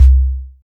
32SYN.BASS.wav